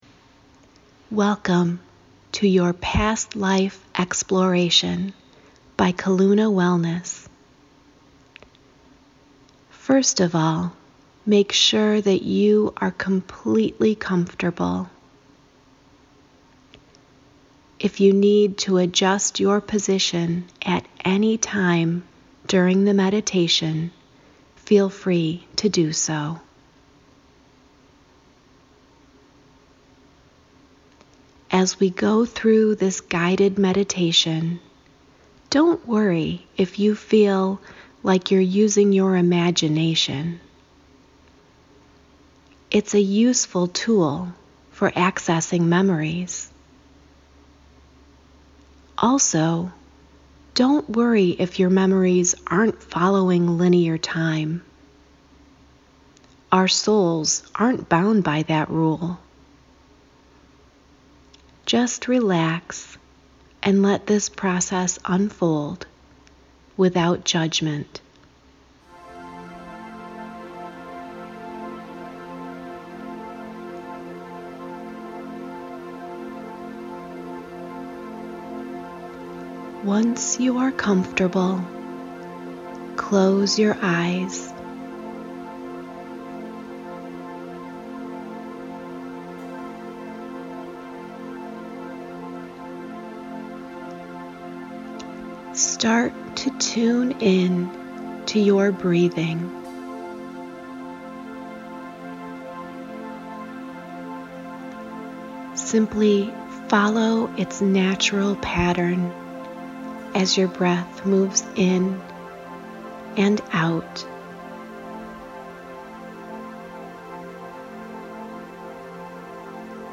So I’ve decided to re-share my Past Life Exploration meditation: